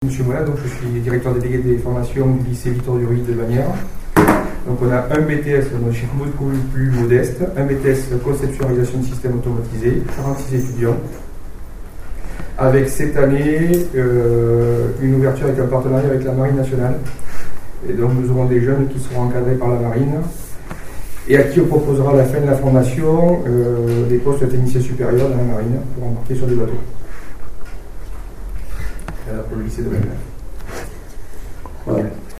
Chaque responsable d’établissement est ensuite intervenu.
Les interventions